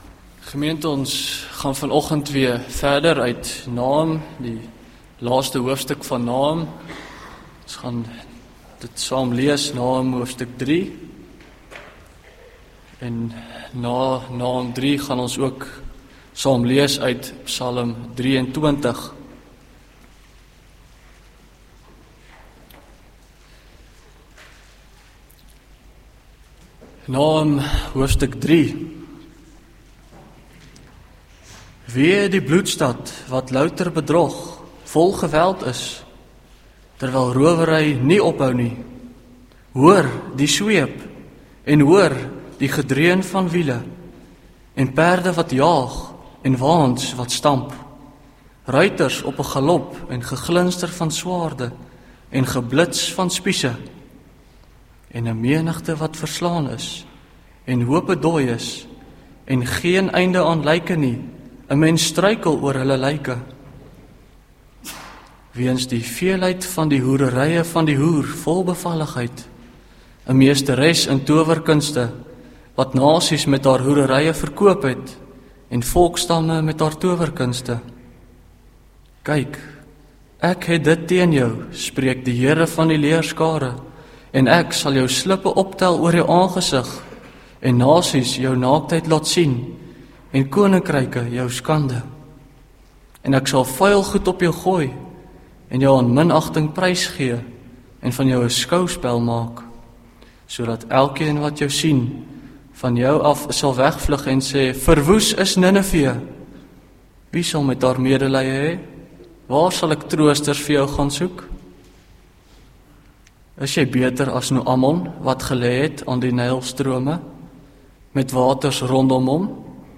2024-07-28 Teks: Nahum 3 Audio Link: Link Preek Inhoud